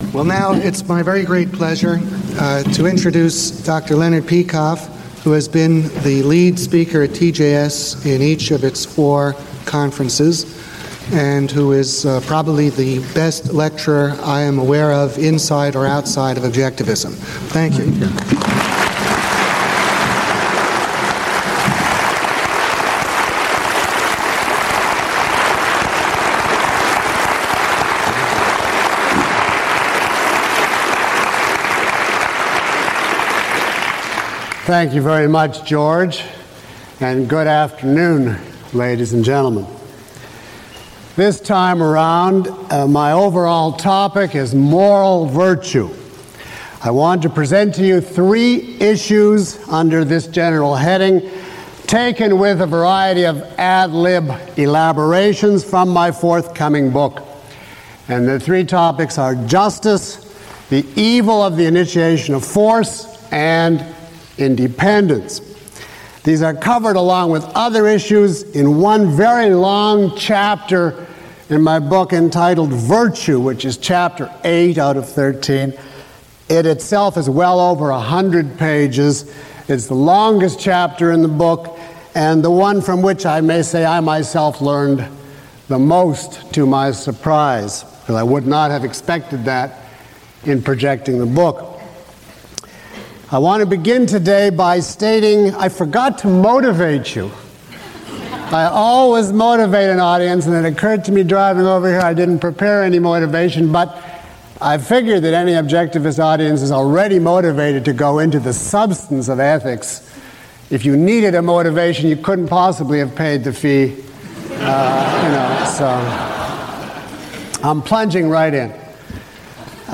Lecture 01 - Moral Virtue.mp3